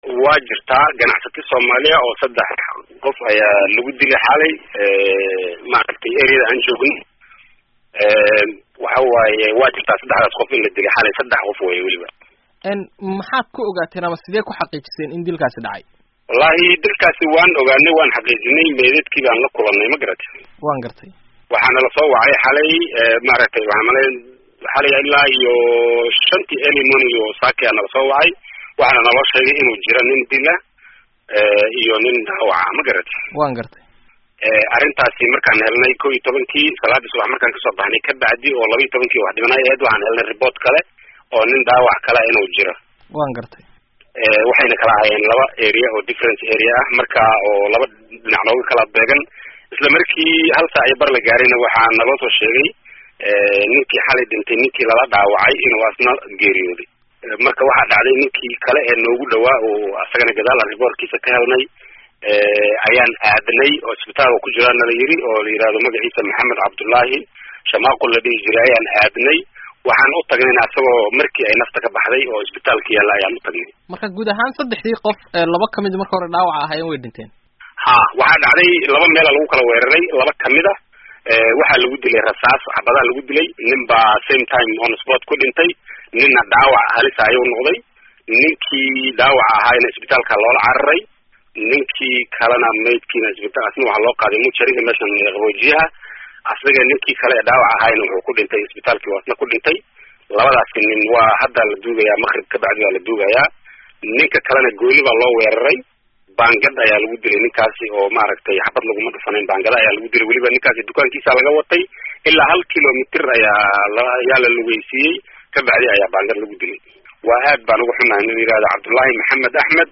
Waraysiga Ganacsatada lagu Dilay Koonfur Afrika